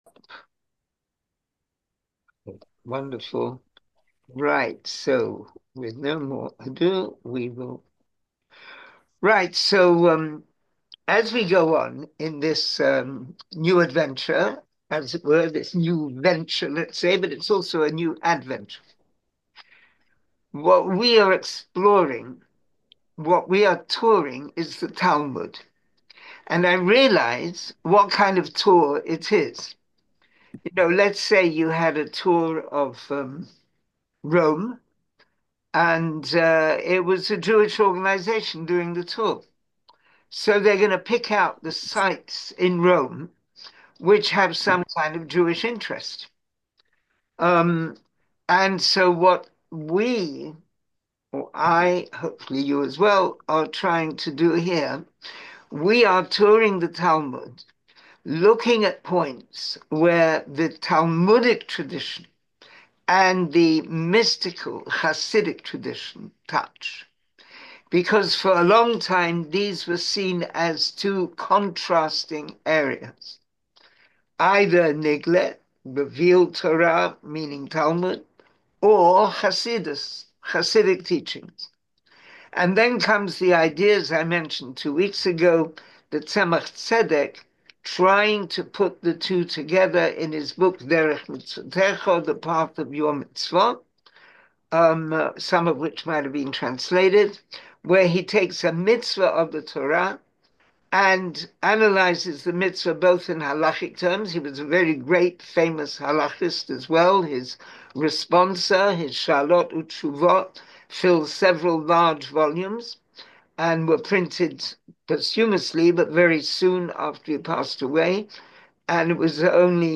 Class audio & video